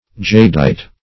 Jadeite \Jade"ite\, n. (Min.)